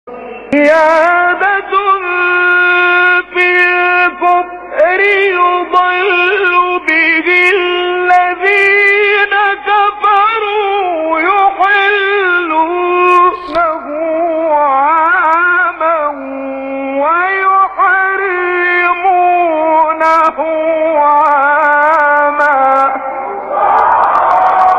گروه فعالیت‌های قرآنی: مقاطع زیبا و شنیدی از قاریان مصری و ایرانی که در کانال‌ها و گروه‌های قرآنی تلگرام منتشر شده است، ارائه می‌شود.